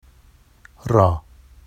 rɑ_183.mp3